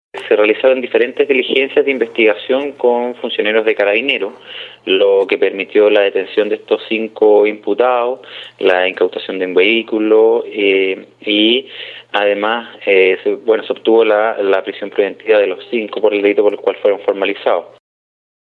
El Fiscal de Castro, Luis Barría, señaló tras la audiencia de control de la detención y formalización de la investigación que la detención de los sujetos fue la conclusión de una fuerte investigación realizada sobre estos ilícitos.
04-FISCAL-CASTRO.mp3